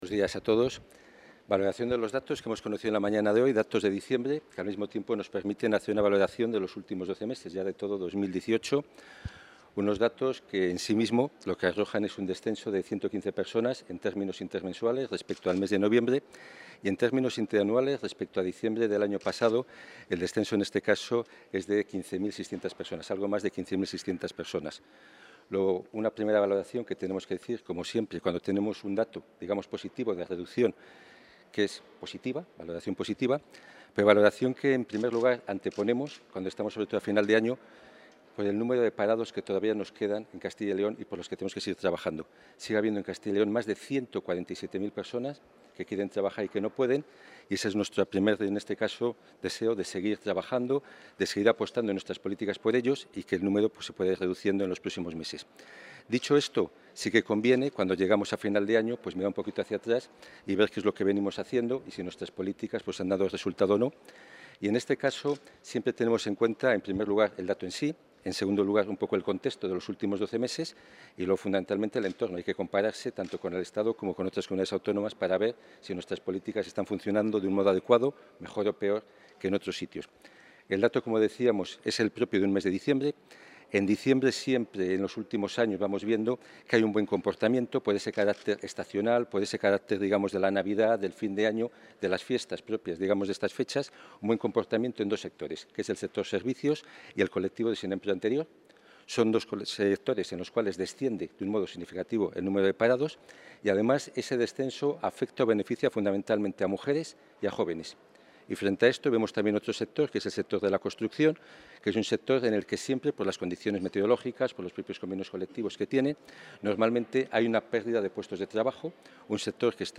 El viceconsejero de Empleo y Diálogo Social, Mariano Gredilla, ha valorado hoy los datos del paro del mes de diciembre y de 2018.